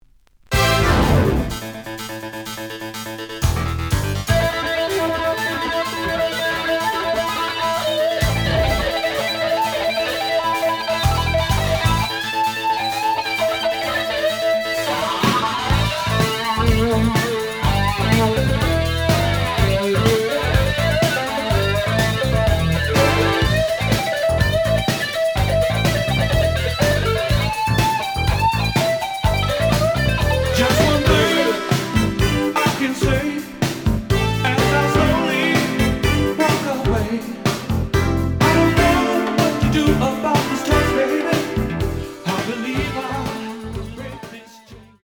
試聴は実際のレコードから録音しています。
●Genre: Soul, 80's / 90's Soul